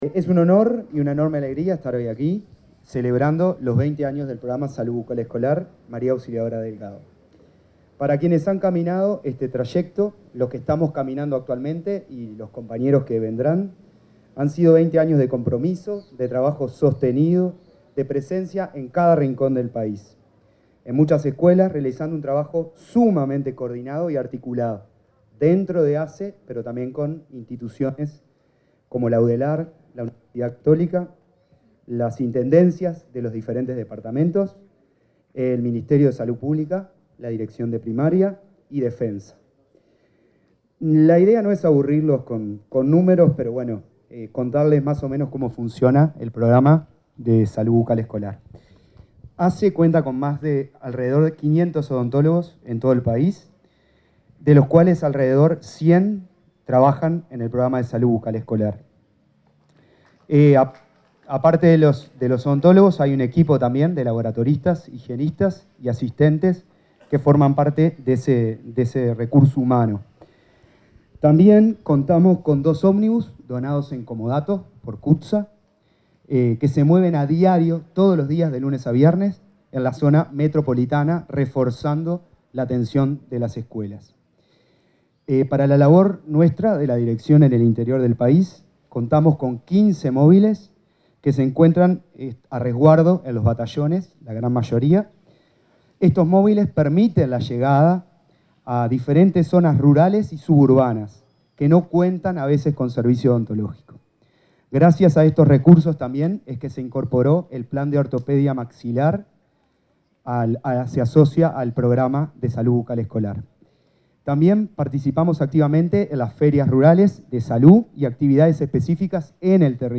Palabras de autoridades en celebración del Plan de Salud Bucal Escolar
Palabras de autoridades en celebración del Plan de Salud Bucal Escolar 04/06/2025 Compartir Facebook X Copiar enlace WhatsApp LinkedIn En el 20.° aniversario del Programa Nacional de Salud Bucal Escolar María Auxiliadora Delgado, se expresaron la ministra de Salud Pública, Cristina Lustemberg; el director de Salud Bucal, Agustín Dean; el presidente de la Administración Nacional de Educación Pública, Pablo Caggiani, y la vicepresidenta de la República, Carolina Cosse.